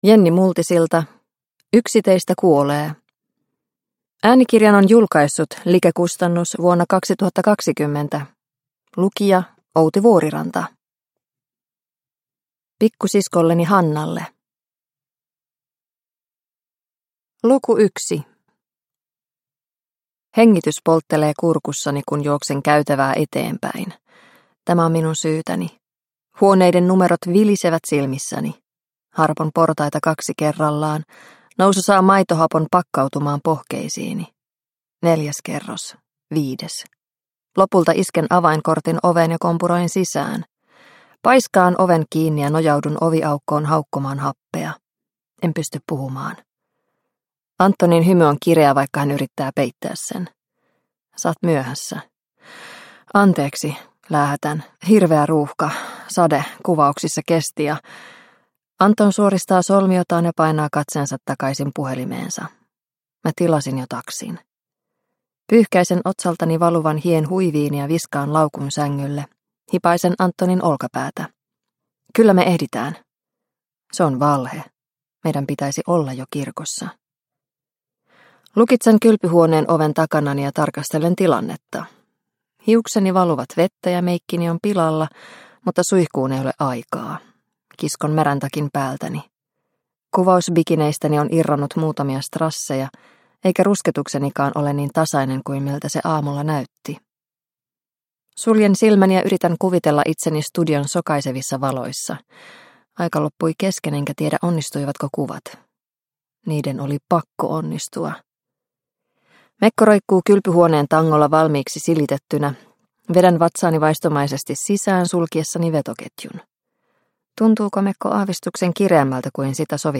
Yksi teistä kuolee – Ljudbok – Laddas ner